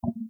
Collision-small-wooden.wav